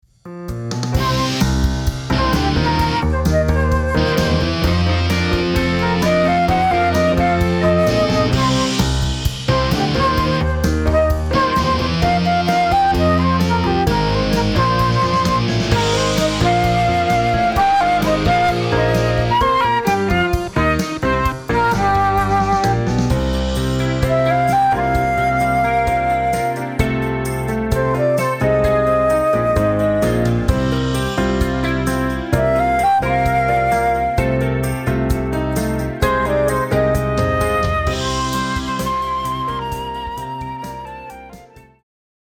Range: G to top G.